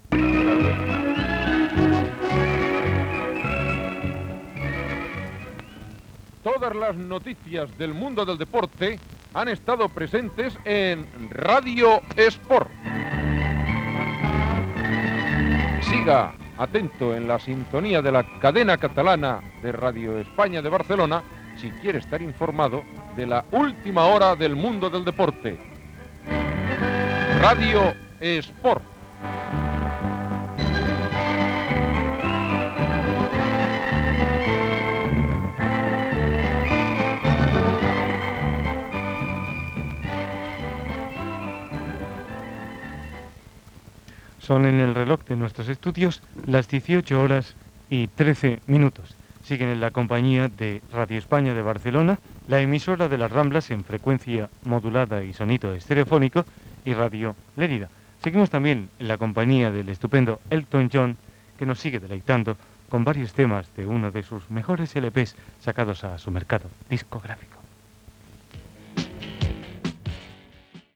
Careta de sortida del programa, hora, identificació i tema musical.